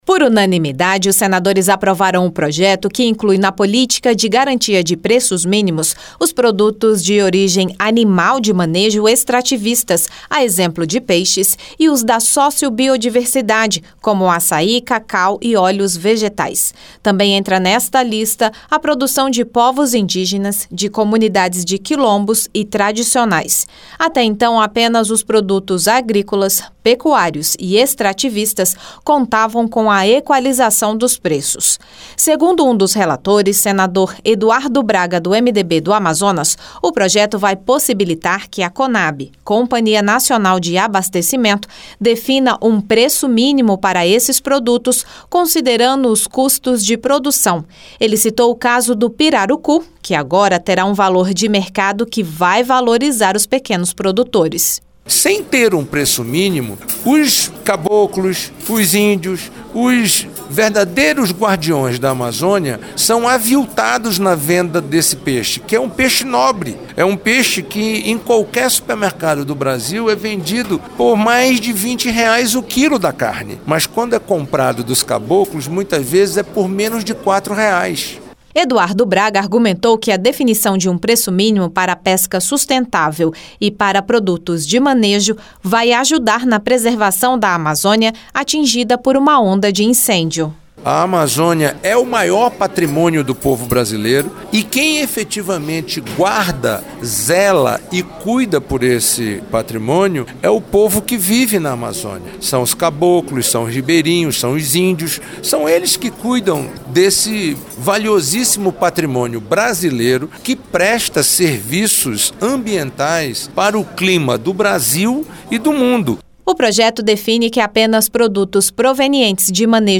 O Plenário aprovou o projeto que inclui na Política de Garantia de Preços Mínimos os produtos de origem animal de manejo extrativista, a exemplo de peixes. Segundo um dos relatores, senador Eduardo Braga (MDB-AM), a proposta vai beneficiar a produção do pirarucu, que é comprado a R$ 4 o quilo e vendido a R$ 20.